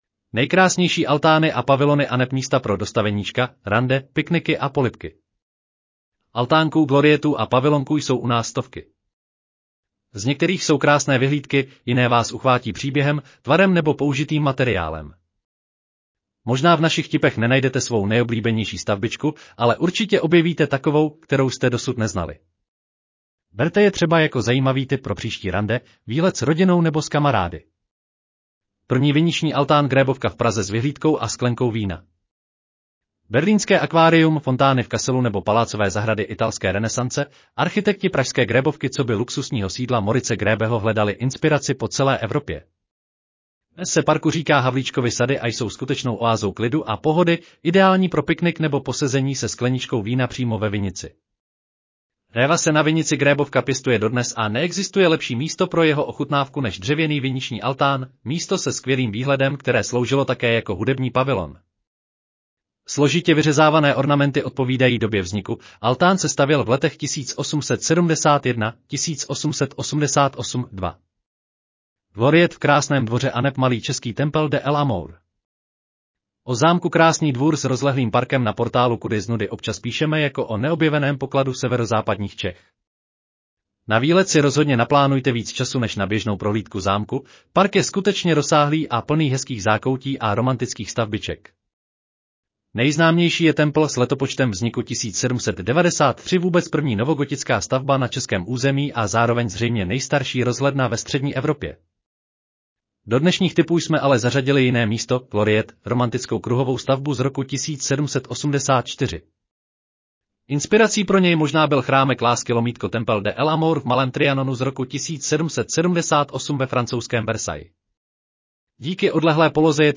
Audio verze článku Nejkrásnější altány a pavilony aneb místa pro dostaveníčka, rande, pikniky a polibky